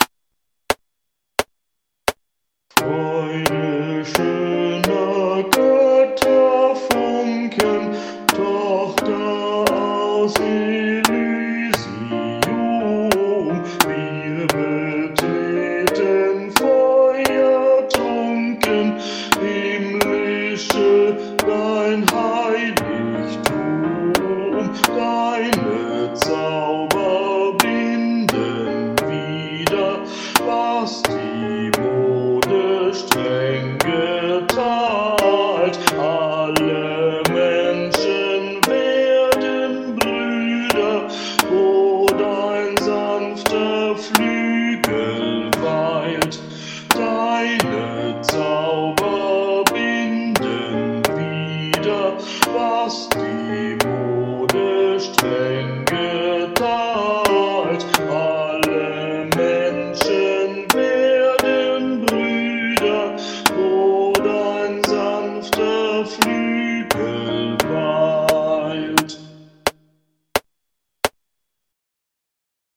Übe-Tracks (MP3)
Klassisch
Bass   Klavier
Ode_an_die_Freude_klassisch_Bass.mp3